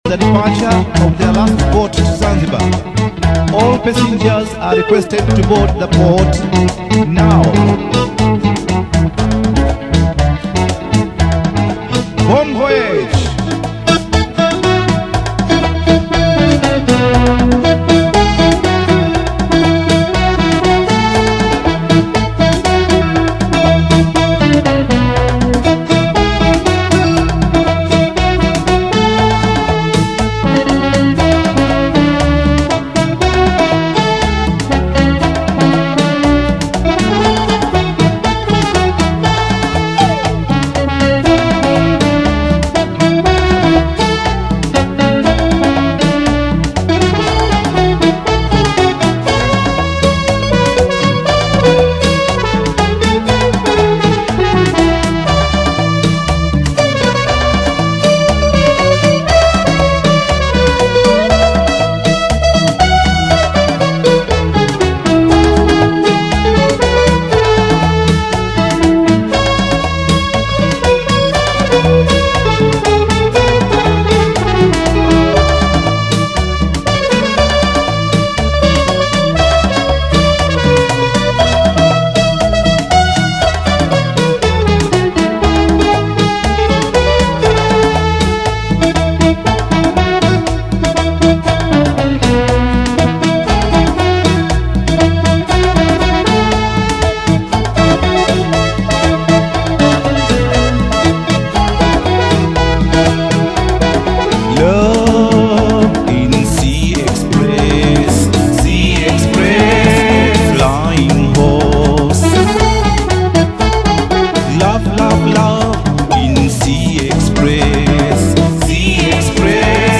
Best Taarab musician